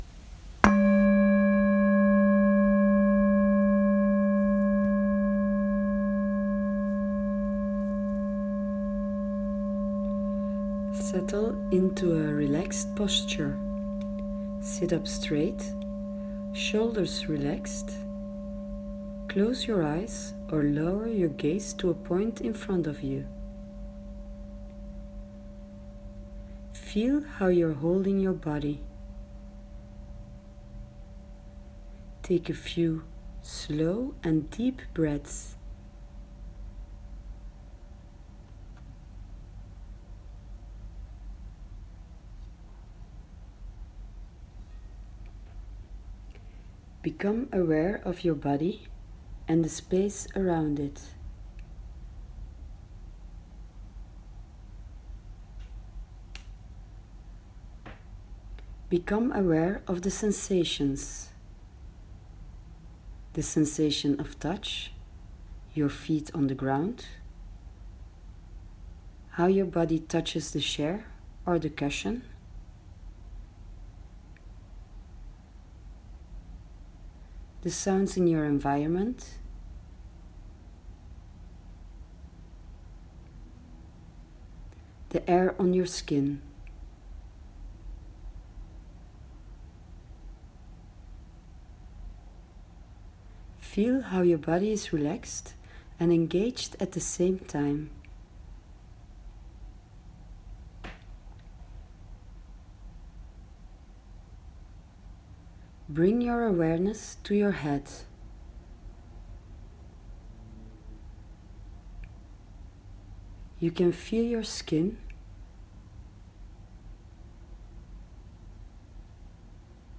Meditation for Thursday 14/12